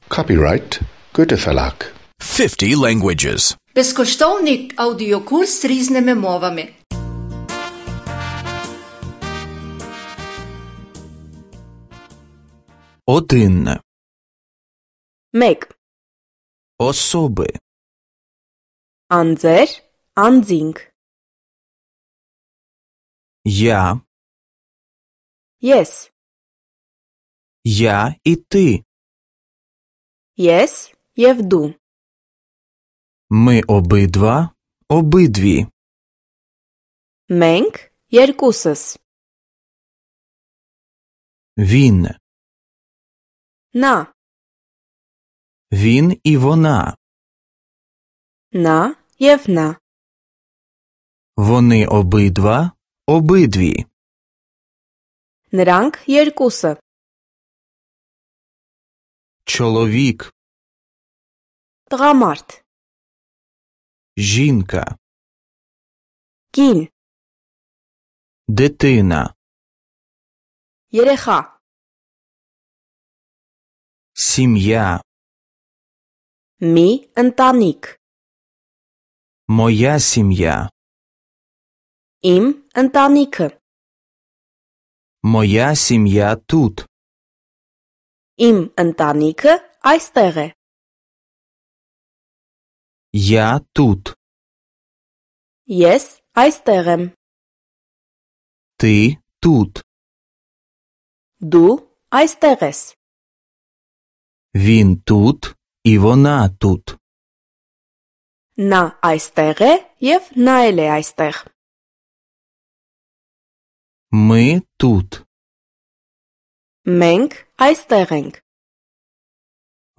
Тут ви зможете послухати, як лунає вірменська мова на вустах носія мови.
Аудіозапис з прикладами правильної вимови ви знайдете знизу на сторінці.